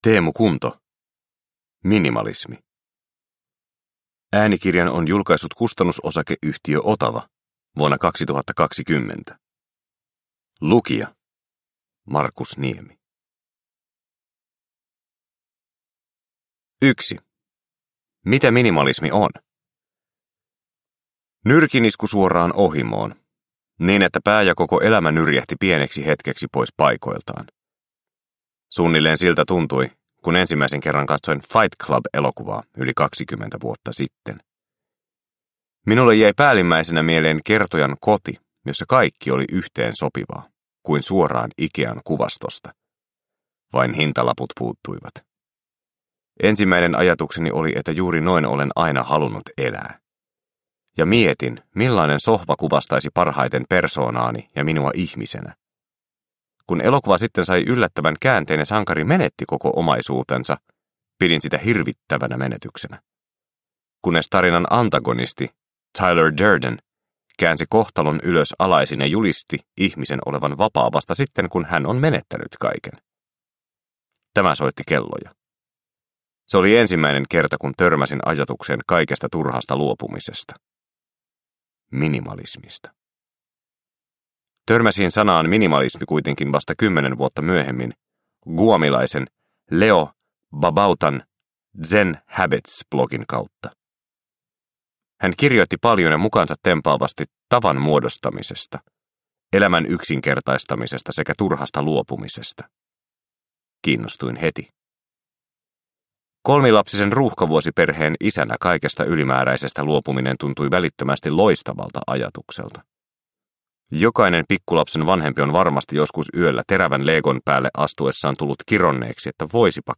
Minimalismi – Ljudbok – Laddas ner